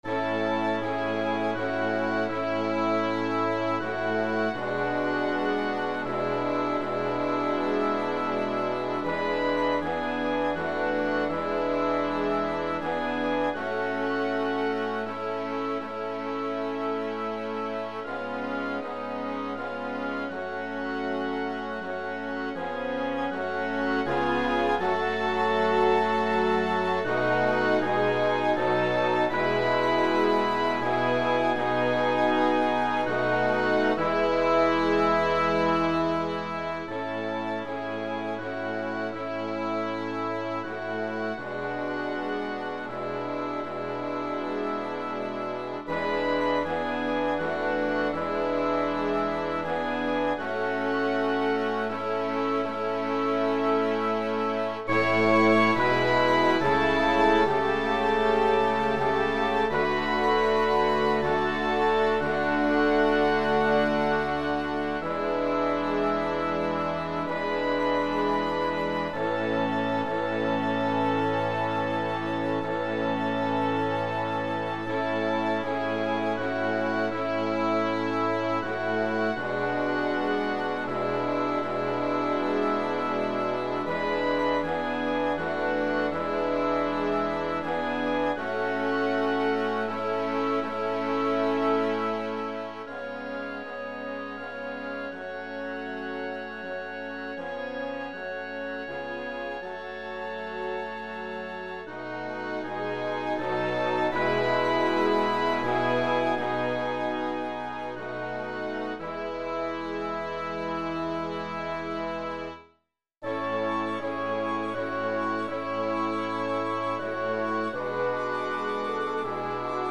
This is one of my chorales in my series of traditional hymns arranged for band in each of the major key signatures.
French Horn
Originally, it was in 6/8 and the key of Ab but this is in 3/4 and the key of A.